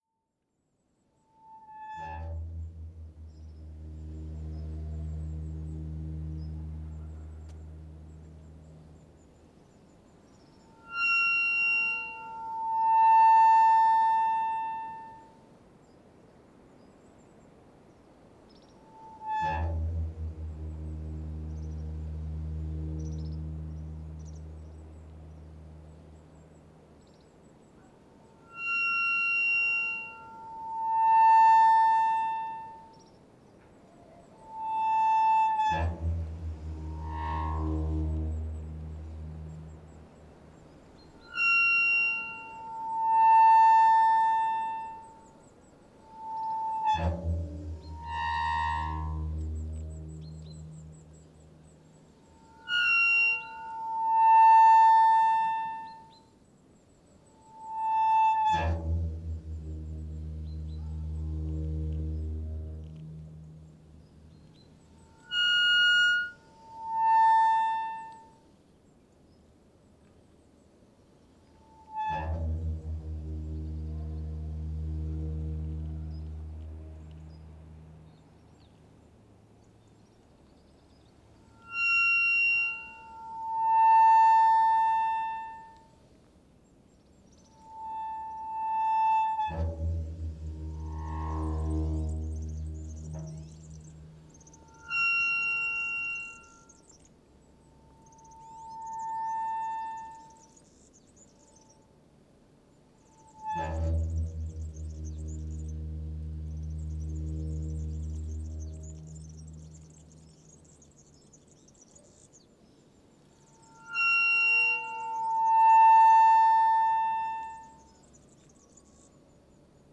O ponto intermédio do percurso iniciado frente ao cemitério de Sanguinhedo de Côta é assinalado através da activação sonora do portão da capela da Senhora do Freixo.
NODAR.00513 – Côta: Portão de Capela na Rua Senhora do Freixo em Sanguinhedo de Côta